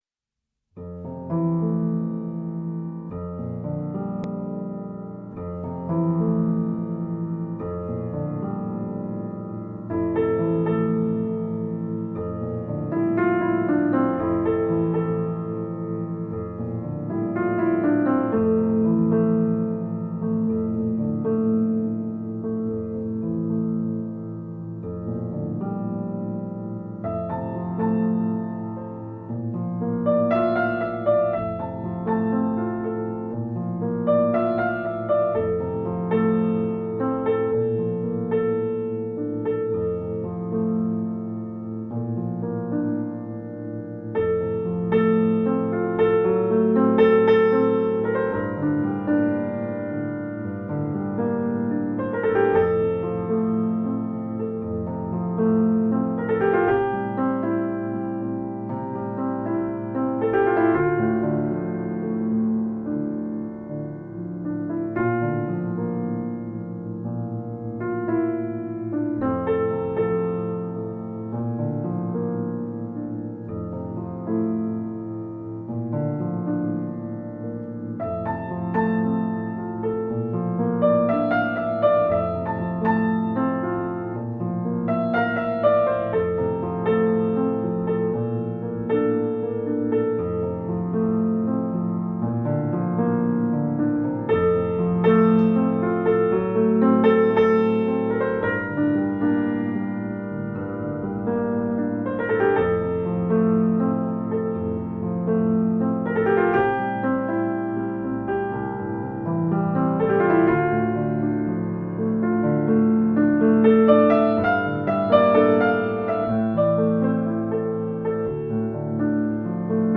La fréquence 95 Hz ( anti-âge) facilite la reproduction des cellules souches
La-frequence-95-Hz-anti-age-la-reproduction-des-cellules-souches.mp3